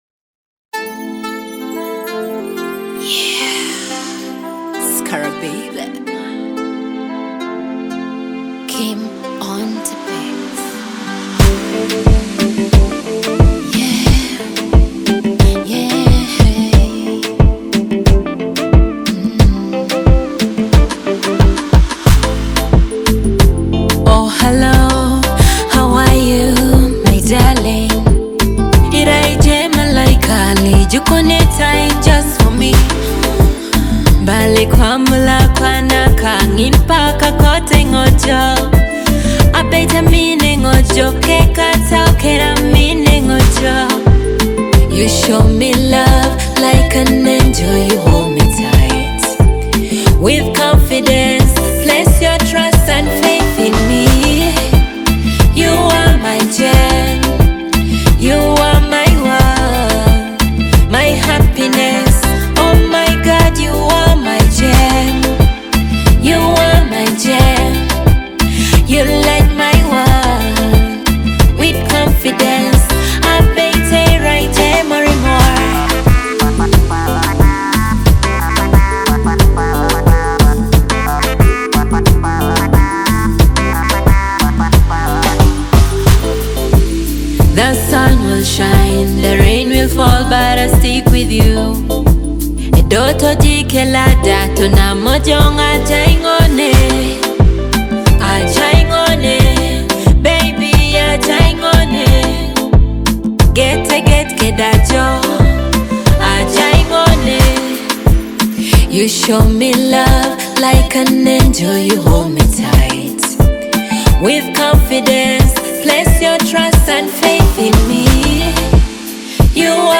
Teso music
a captivating blend of rhythmic beats and powerful vocals.